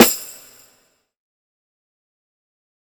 020_Lo-Fi Big Perc.wav